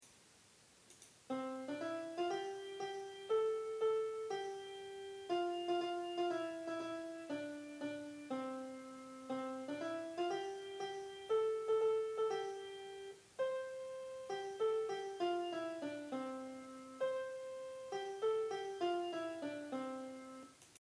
Dutch children's songs with English translation.
klap-eens-handjes-muziek.mp3